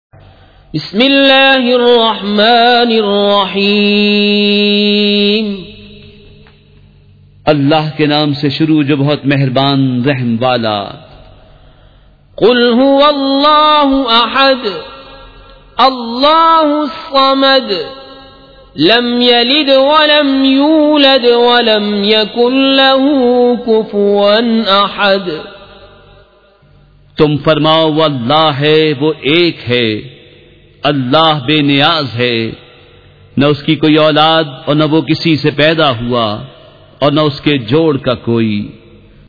سورۃ الاخلاص مع ترجمہ کنزالایمان ZiaeTaiba Audio میڈیا کی معلومات نام سورۃ الاخلاص مع ترجمہ کنزالایمان موضوع تلاوت آواز دیگر زبان عربی کل نتائج 3142 قسم آڈیو ڈاؤن لوڈ MP 3 ڈاؤن لوڈ MP 4 متعلقہ تجویزوآراء